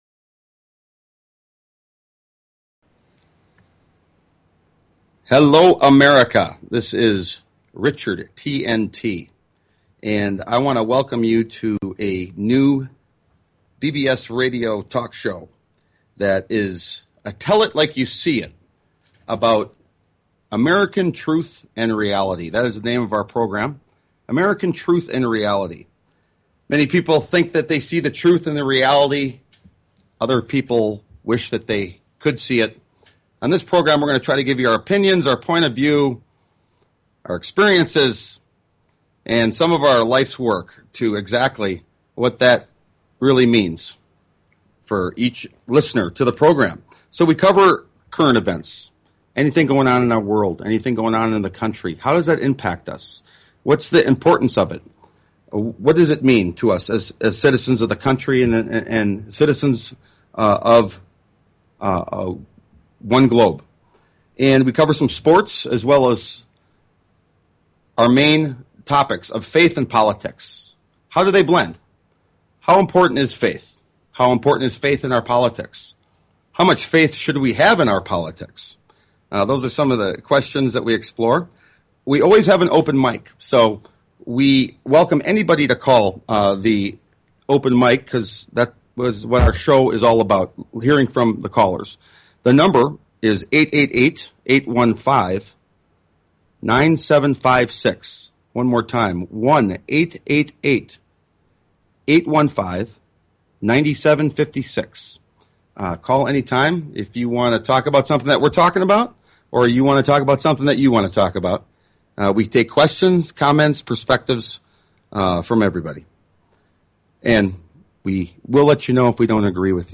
Talk Show Episode, Audio Podcast, American_Truth_and_Reality and Courtesy of BBS Radio on , show guests , about , categorized as